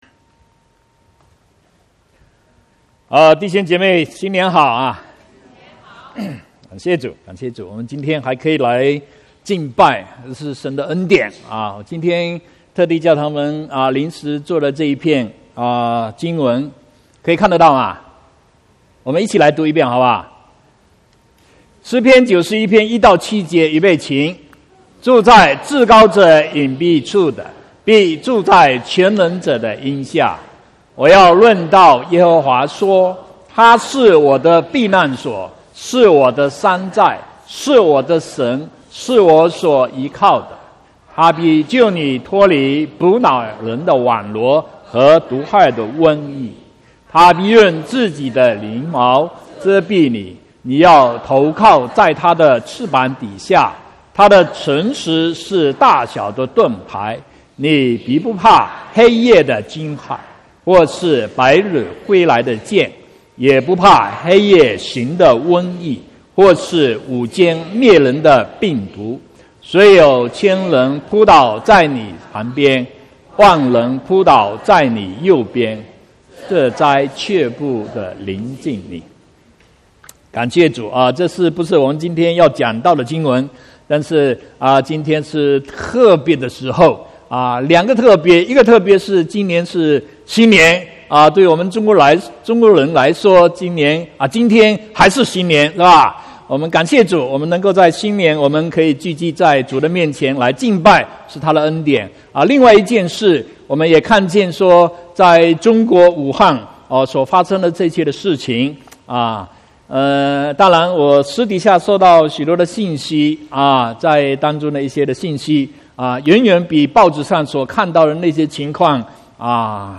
26/1/2020 國語堂講道